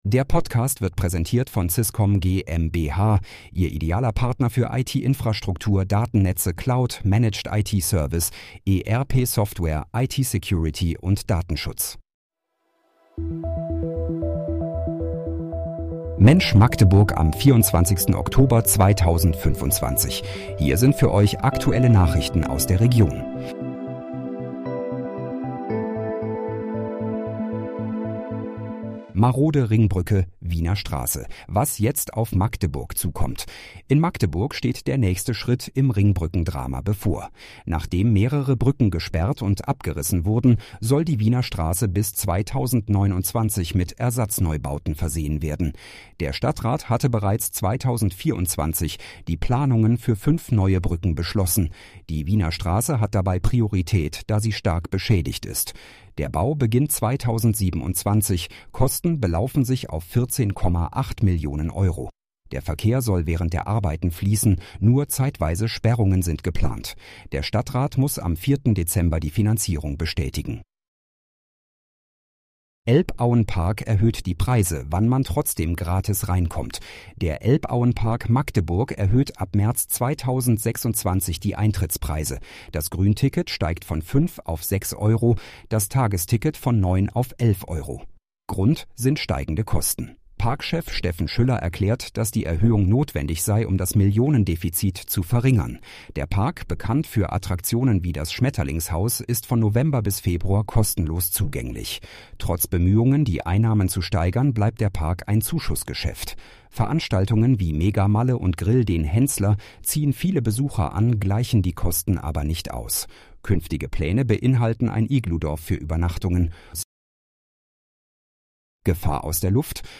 Mensch, Magdeburg: Aktuelle Nachrichten vom 24.10.2025, erstellt mit KI-Unterstützung
Nachrichten